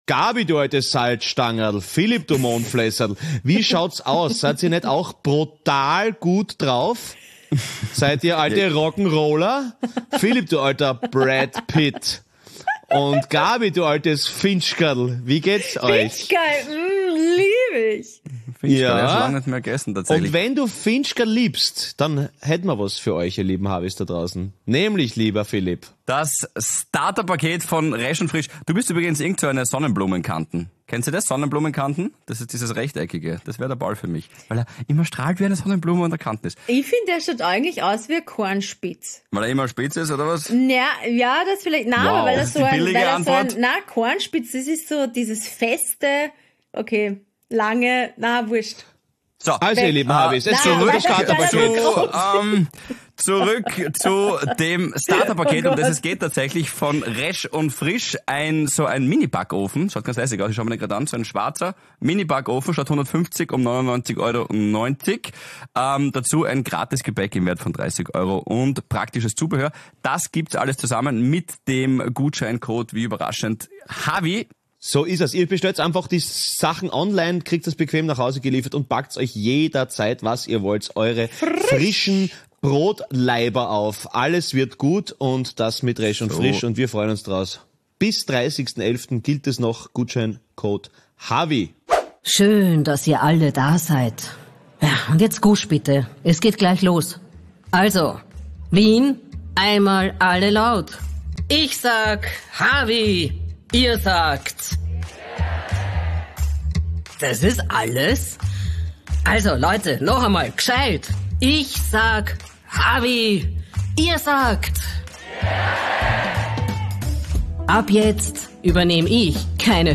Hawi D'Ehre LIVE vom 16.09.2023 aus dem GLOBE Wien.